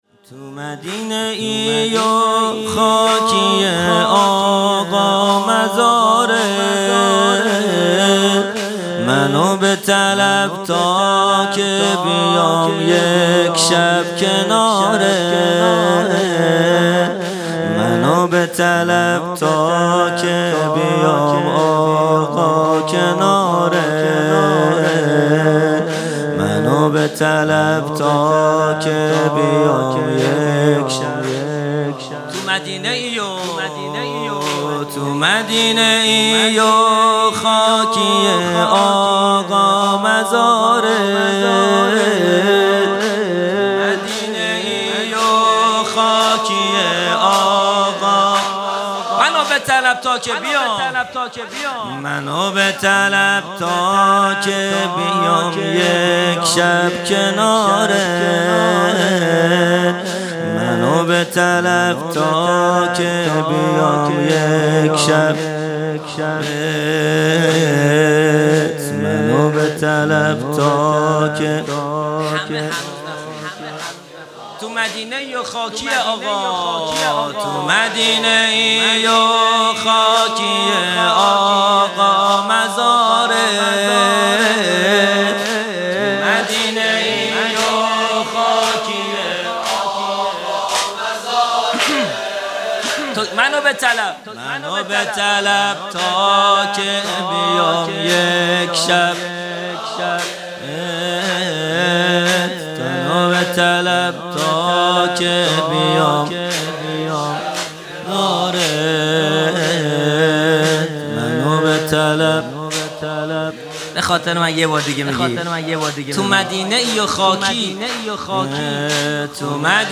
زمینه | تو مدینه ای و خاکی آقا مزارت | 22 اردیبهشت 1401
جلسۀ هفتگی | سالروز تخریب بقیع | پنجشنبه 22 اردیبهشت 1401